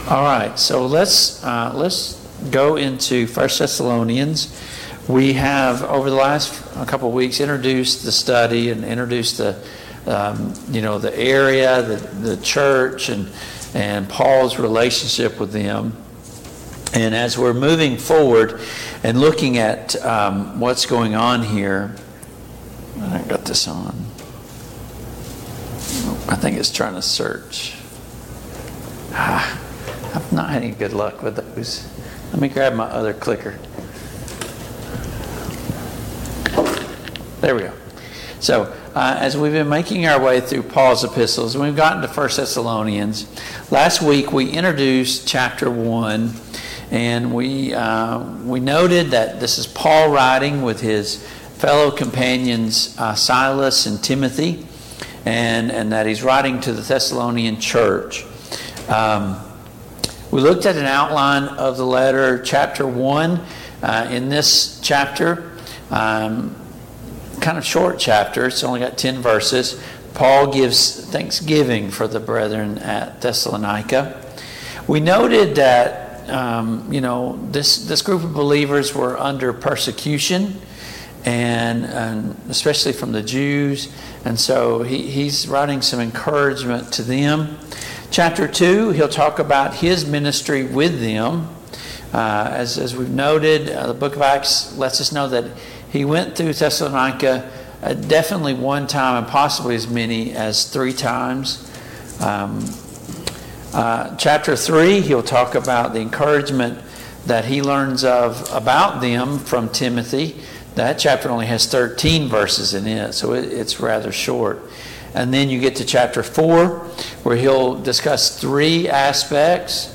Passage: 1 Thessalonians 1:1-12; 1 Thessalonians 2:1-4 Service Type: Mid-Week Bible Study